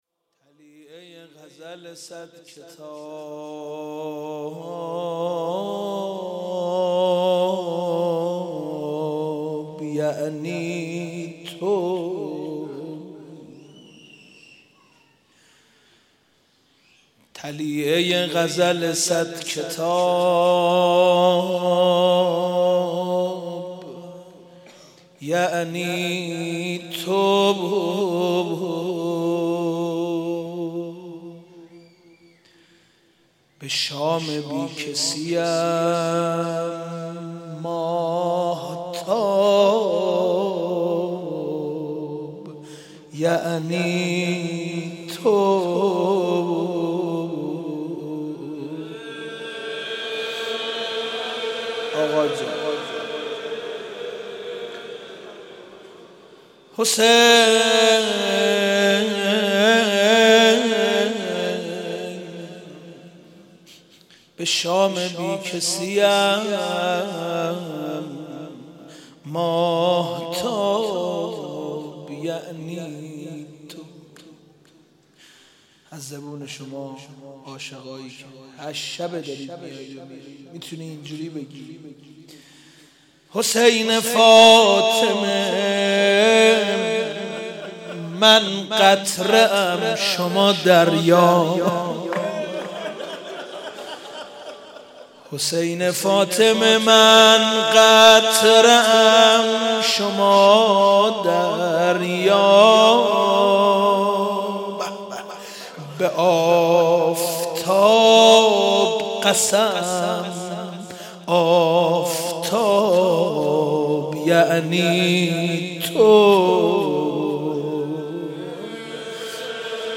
مراسم شب هشتم محرم ۱۳۹۲
مداحی